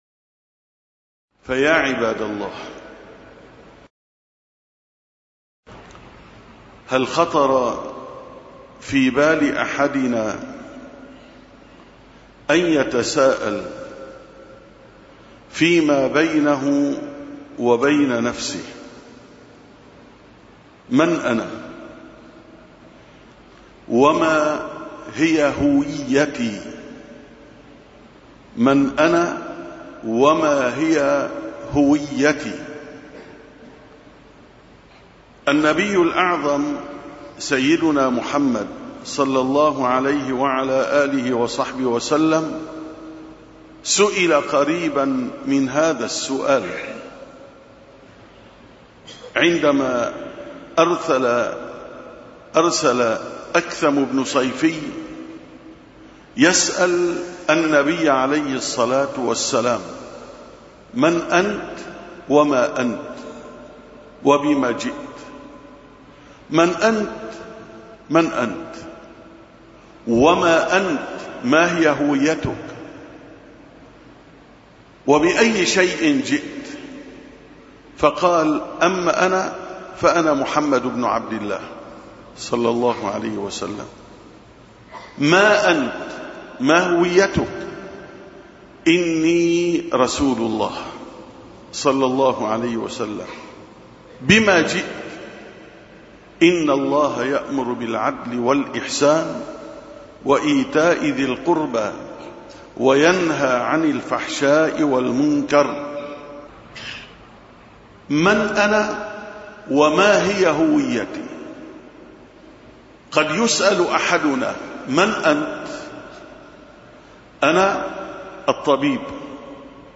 884ـ خطبة الجمعة: مأساتنا فقد هويتنا